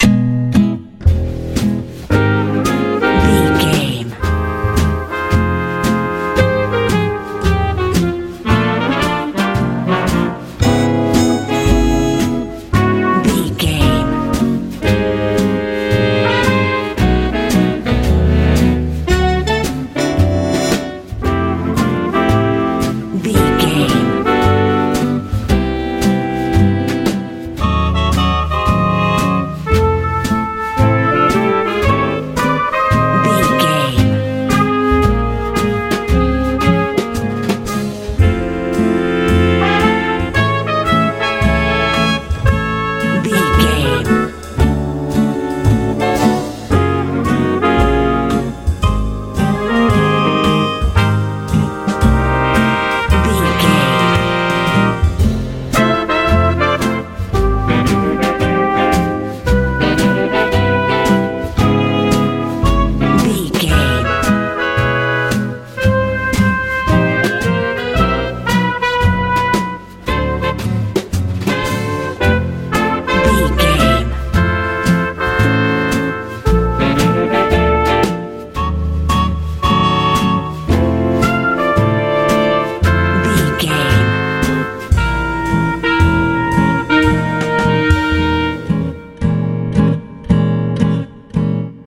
big band jazz feel
Ionian/Major
repetitive
soothing
smooth
horns
acoustic guitar
bass guitar
drums
lively
joyful
bright